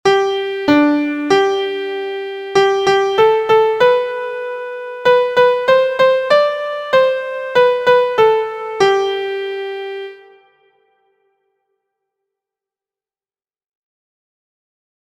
• Origin: USA – Traditional Round
• Key: G Major
• Time: 2/4
• Form: ABCD
• Musical Elements: notes: half, quarter, eighth; round/canon, perfect fourth from the 5th to 1, or So/Do